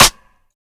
Snare (24).wav